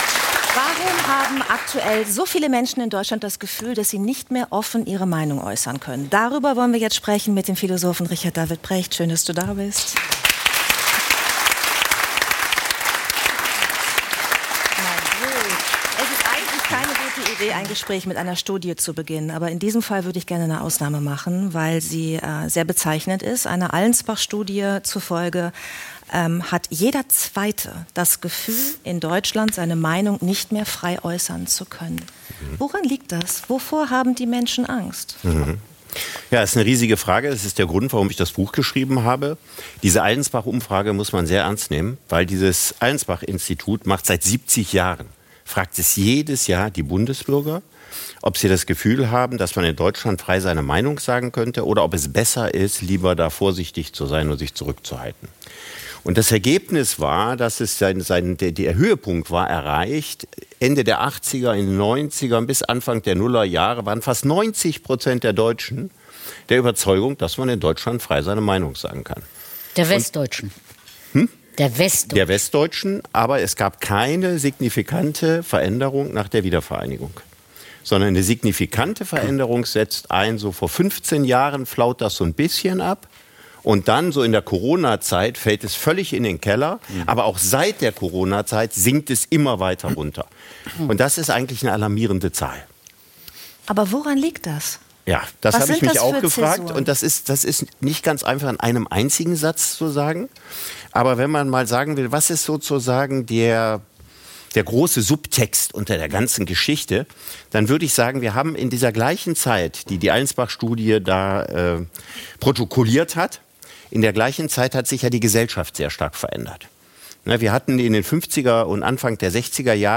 Richard David Precht – Philosoph ~ 3nach9 – Der Talk mit Judith Rakers und Giovanni di Lorenzo Podcast